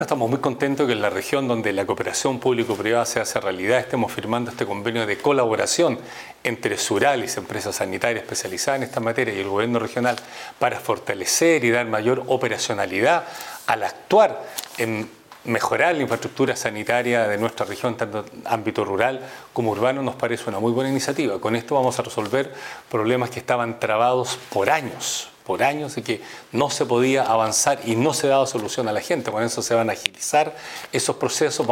El Gobernador Regional de Los Lagos, Patricio Vallespín, destacó que el convenio apunta a destrabar proyectos que por años no han tenido avance en la región.